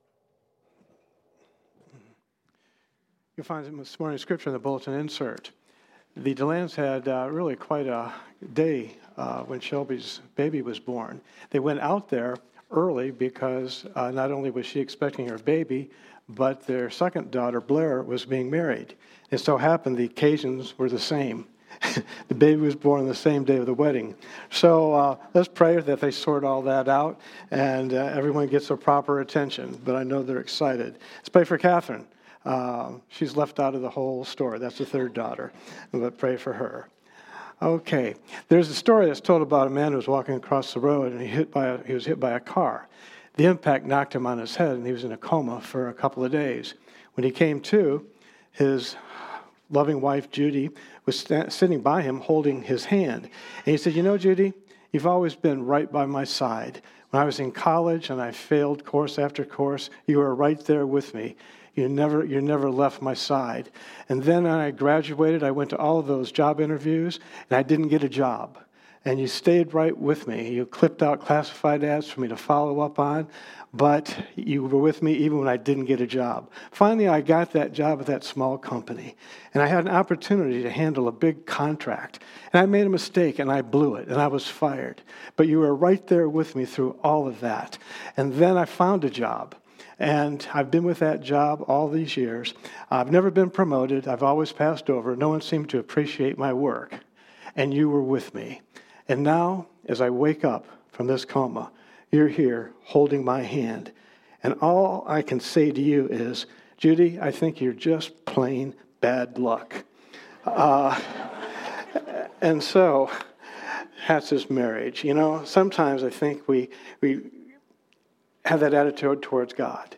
Safe Harbor Community Church Sermons